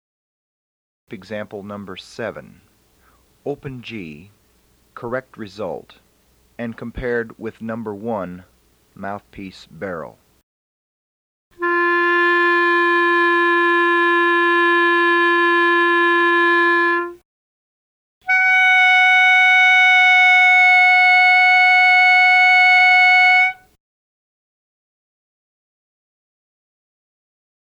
FIRST FULL-CLARINET TONE
CORRECT RESULT – EXAMPLE #7 is analogous to #1